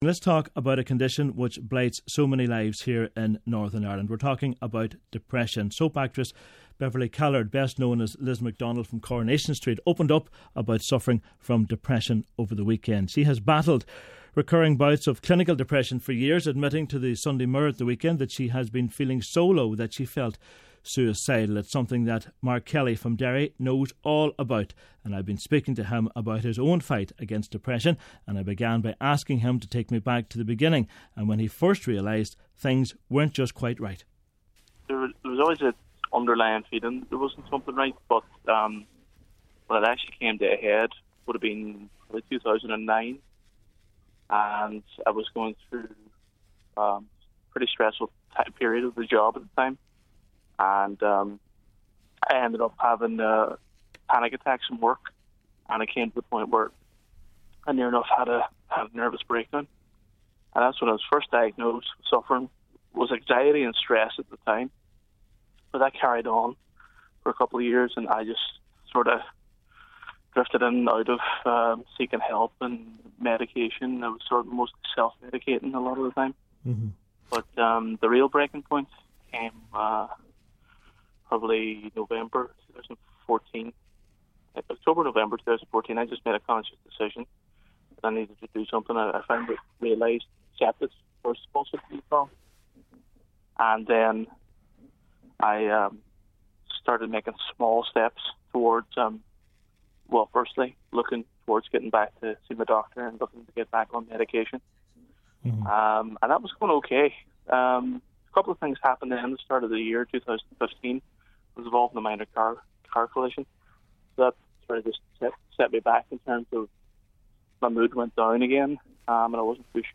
I've been speaking to him about his own fight against depression and I began by asking him to take me back to the beginning and when he first realised when things weren't just quite right.